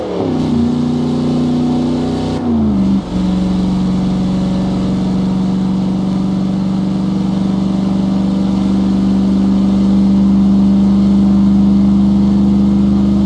Index of /server/sound/vehicles/lwcars/chev_suburban
fourth_cruise.wav